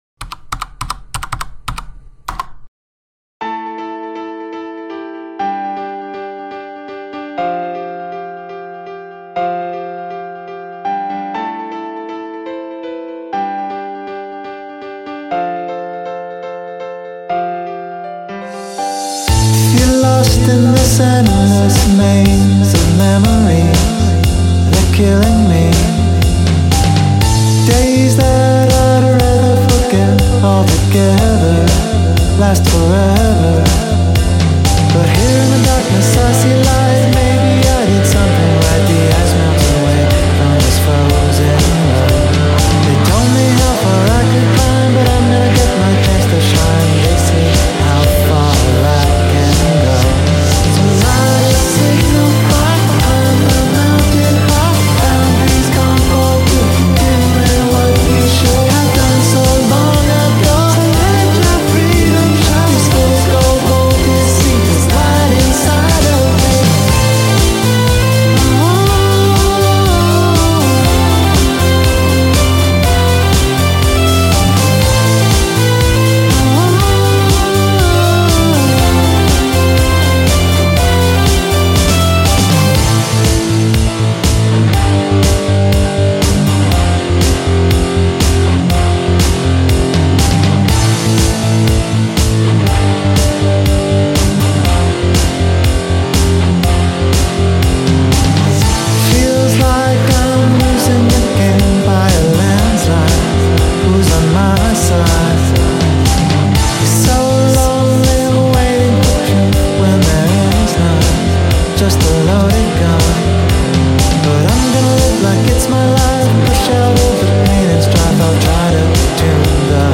Guitars and additional synths
Vocals